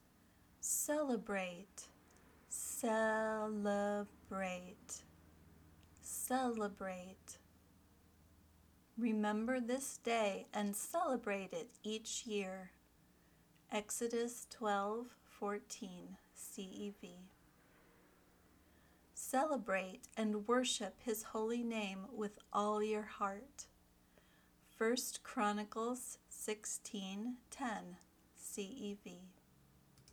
ˈsɛ lə breɪt  (verb)
vocabulary word – celebrate